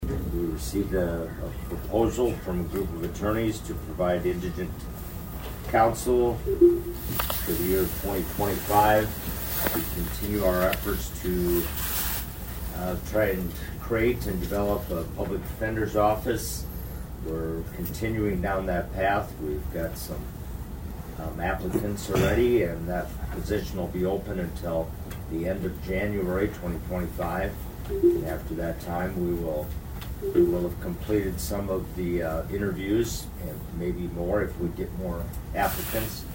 Brown County Commissioner Duane Sutton inform the position will remain open through January.